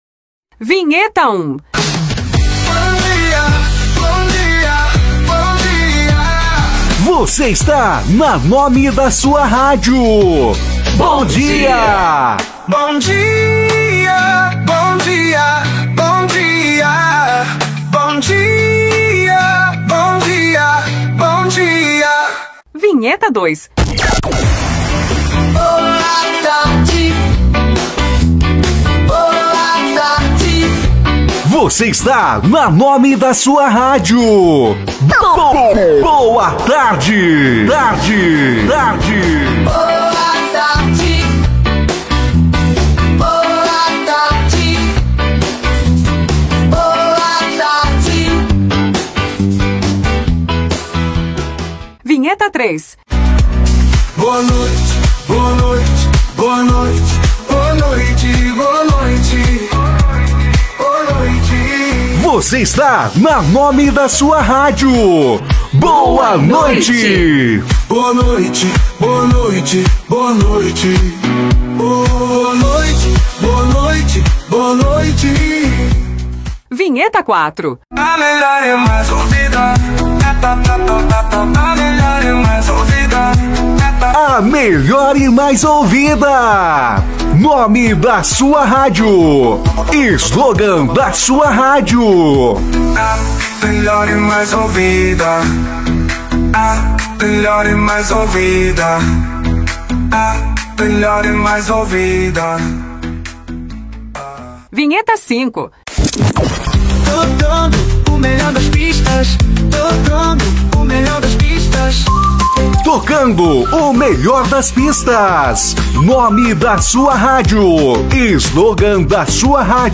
10 Vinhetas pré-montadas de rádio com o nome e slogan
Nome da rádio e slogan gravados com locução profissional